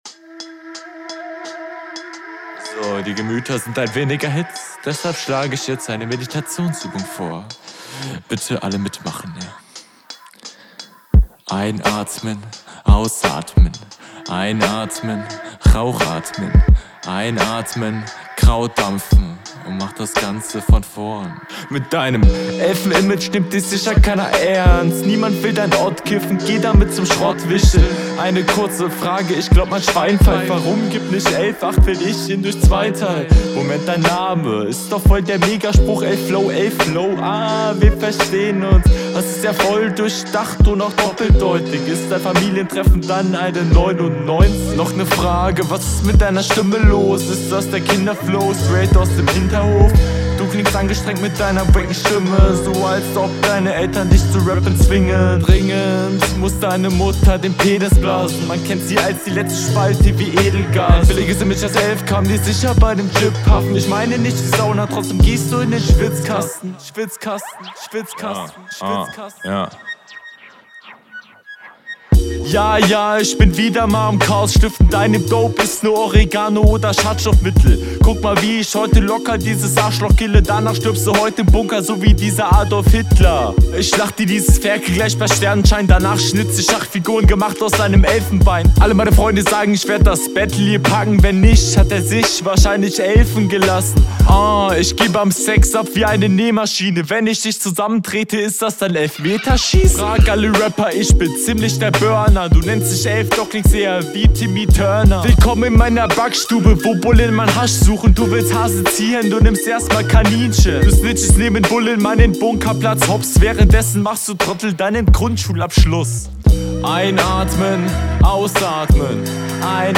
Flow besser als deine RR.
Was ist diese Hook?